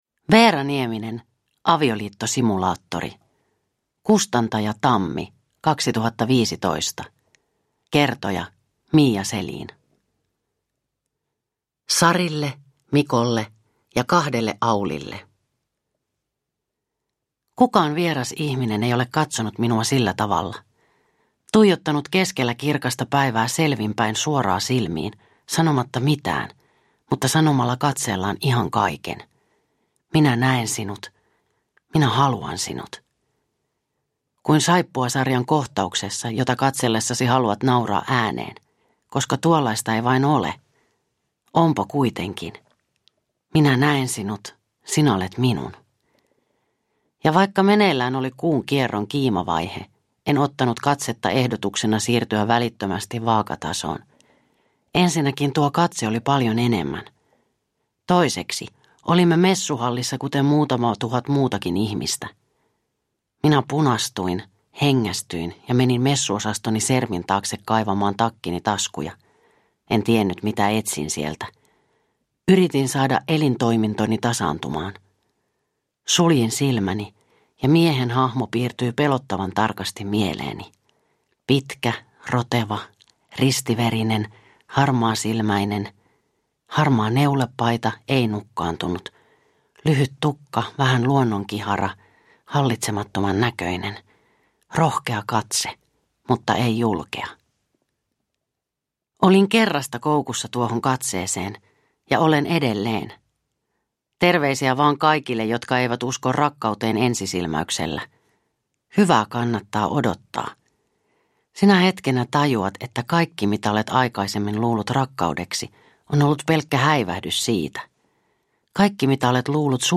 Avioliittosimulaattori – Ljudbok